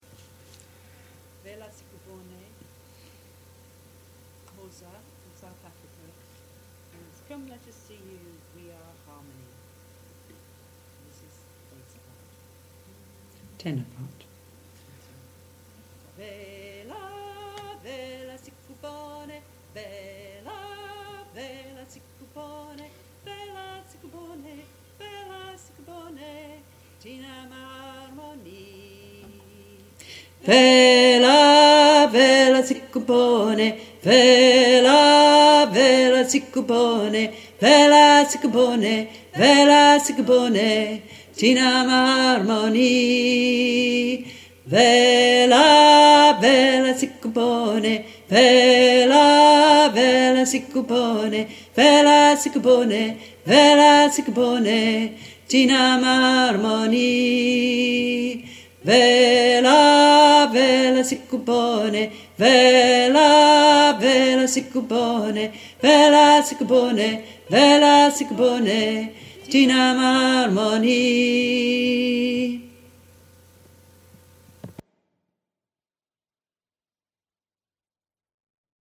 Tenor: Guidance recording of 'Vela Vela' for Cycle of Songs Choir